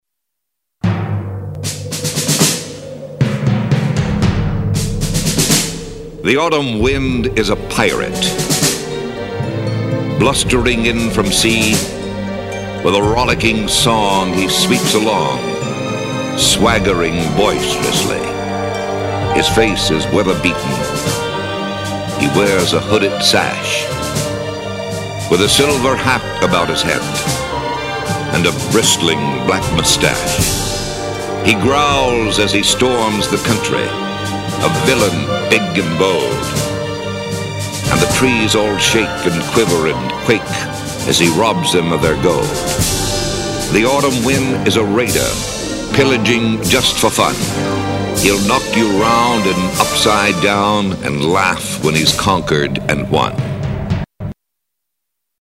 autumn_wind.mp3